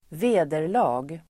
Ladda ner uttalet
Uttal: [²v'e:der_la:g]